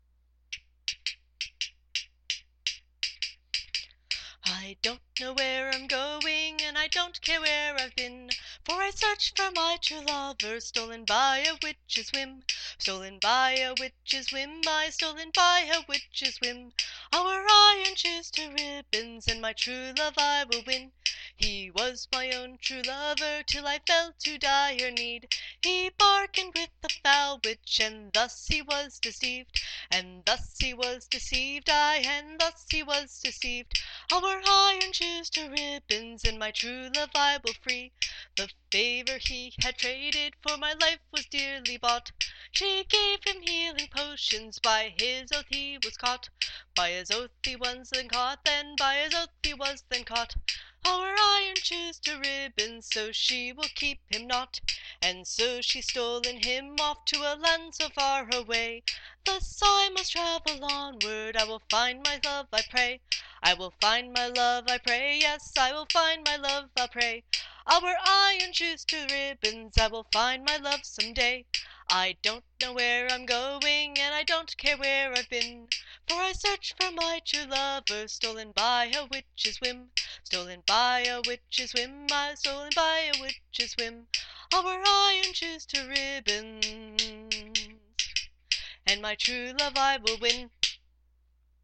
This song is in the style of the Cantigas that pilgrims used to sing on their way to various holy sites in Europe and the Middle East during the Middle Ages.